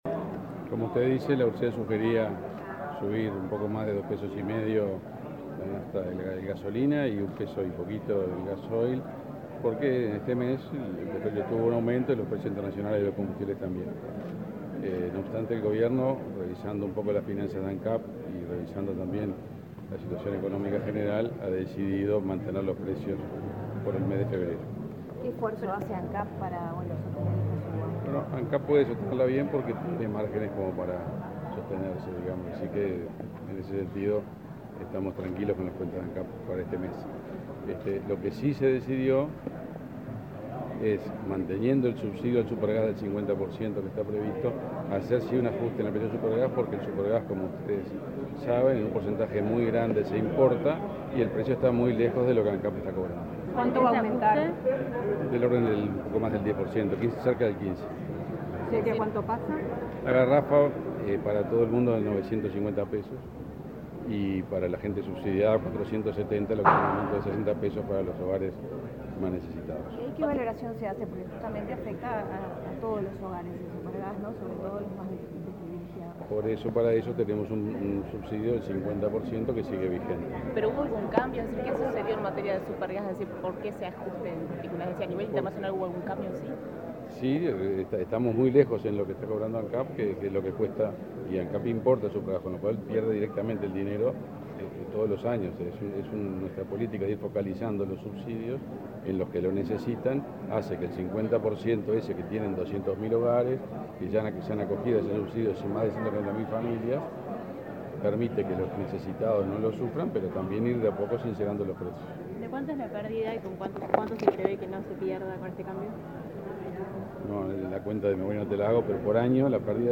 Declaraciones del ministro de Industria, Omar Paganini
El ministro de Industria, Omar Paganini, dialogó con la prensa, este martes 31 en la sede del Sodre, acerca del ajuste de combustibles correspondiente